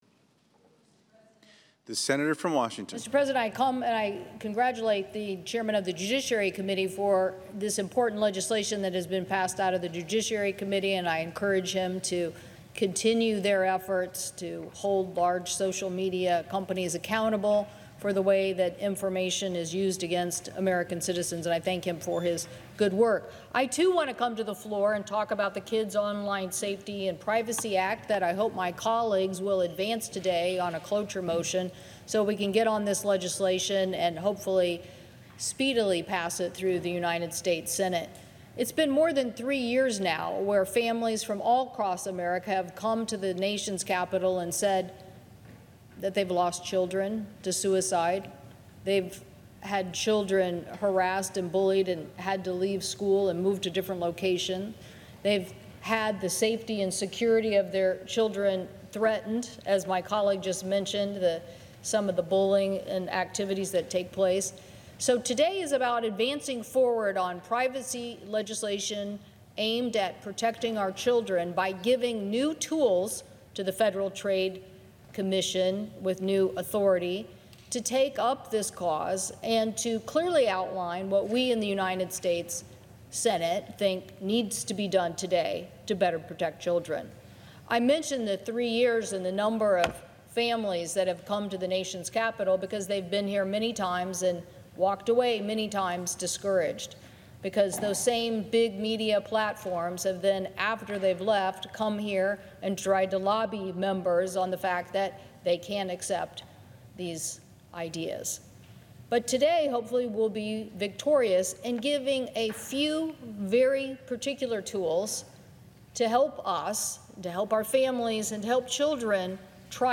WASHINGTON, DC –Today, the U.S. Senate overwhelmingly voted by a vote of 86 to 1 to advance the Children and Teens’ Online Privacy Protection Act (COPPA 2.0) and the Kids Online Safety Act (KOSA), clearing the way for final passage next week. U.S. Senator Maria Cantwell (D-WA), who was instrumental in crafting the package as Chair of the Senate Committee on Commerce, Science, and Transportation, spoke on the Senate floor ahead of today’s procedural vote:
7.25.24 COPPA-KOSA Floor Speech.mp3